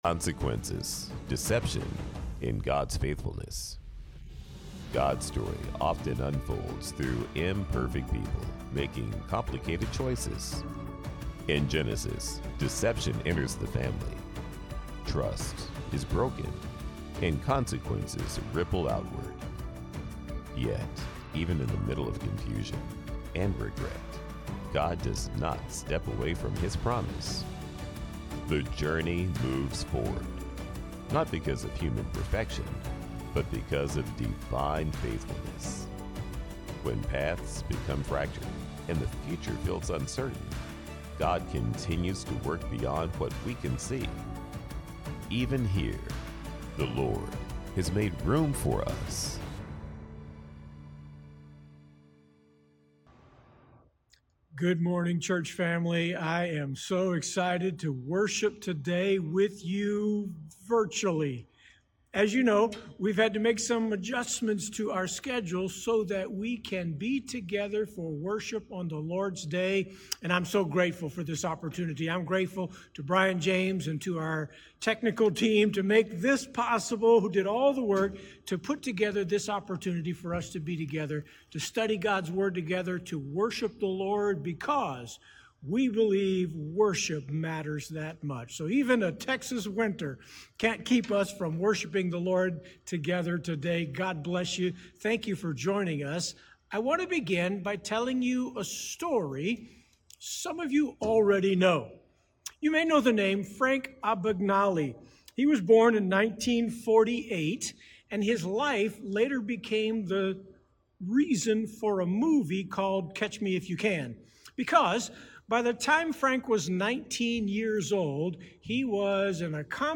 Sermon Listen Worship The story of Jacob deceiving his father Isaac reveals how deception can destroy family relationships across generations.